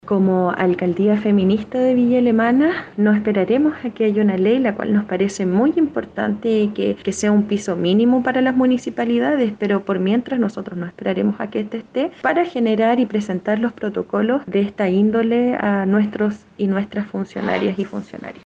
Por su parte, la alcaldesa electa de Villa Alemana, Javiera Toledo, detalló que como municipio feminista, dentro de su proyecto político está gestionar protocolos para defender la integridad de sus trabajares.
alcaldesa-villa-alemana.mp3